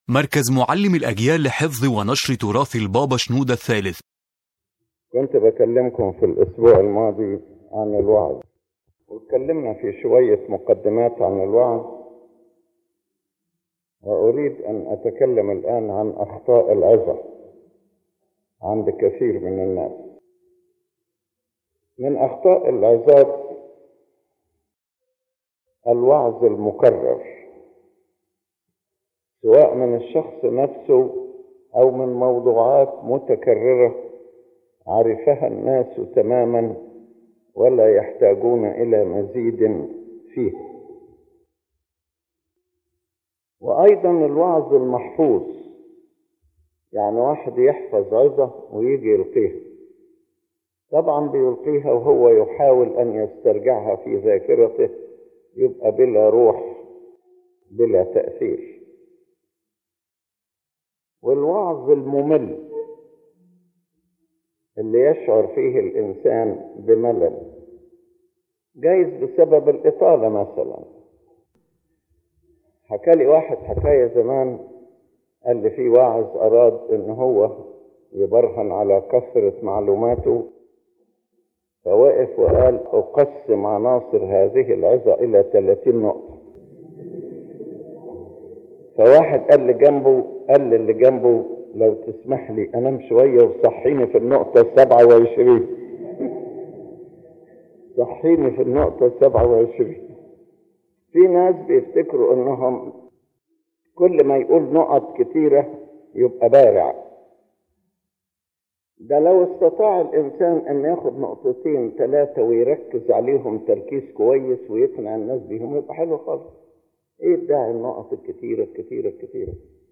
This lecture discusses a number of mistakes that a preacher may fall into while delivering a sermon, and explains how a successful spiritual sermon should be—one that builds the person and leads him toward repentance and spiritual growth.